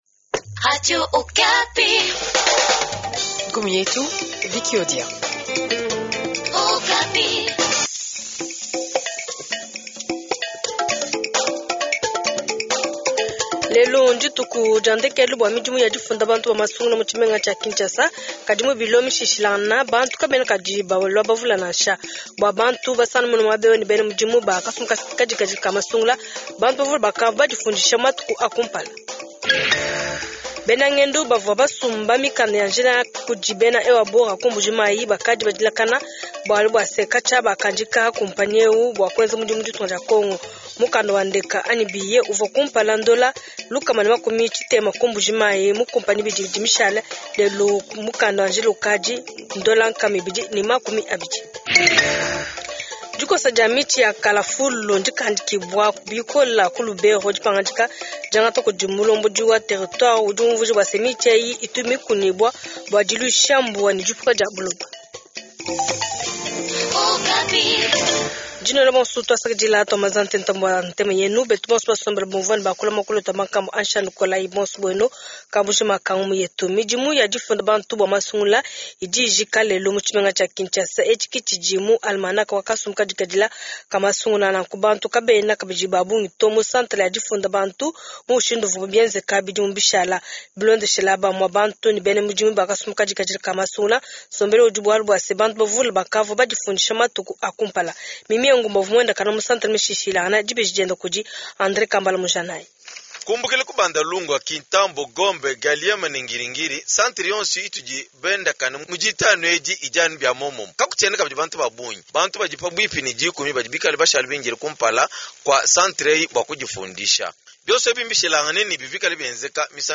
Journal Tshiluba Soir